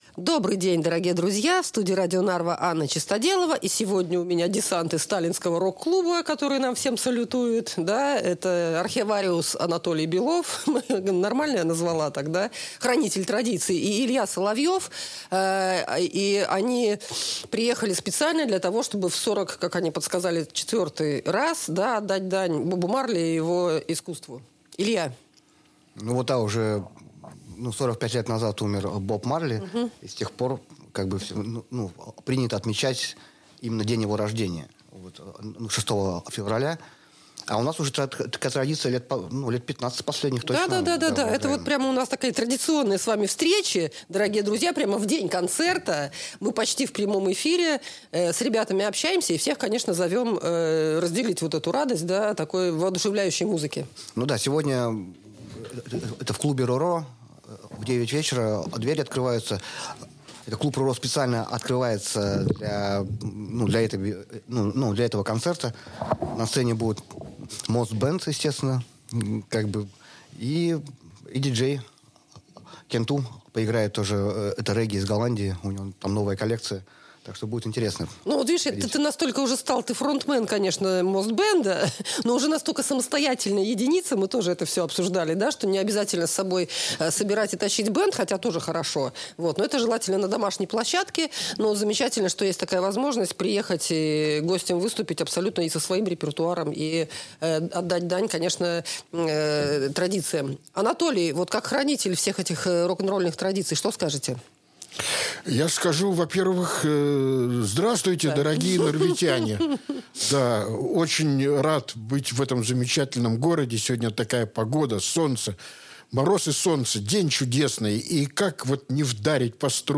Традиционный живой анонс ежегодного события: по пути с вокзала в клуб Ro-Ro